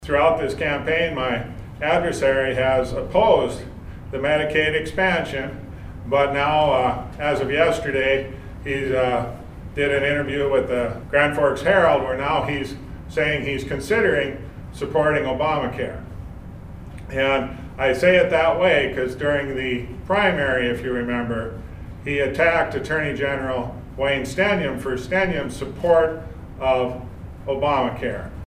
Gubernatorial Candidate Marvin Nelson was part of the press conference at the Gladstone Inn & Suites and opened with the fact that North Dakota was already facing a huge shortfall in the state budget.